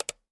camera-switch.wav